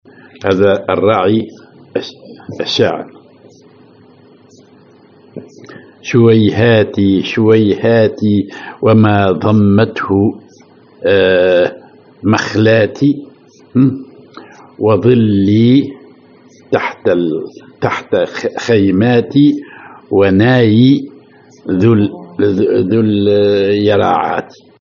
Maqam ar الحسيني
genre نشيد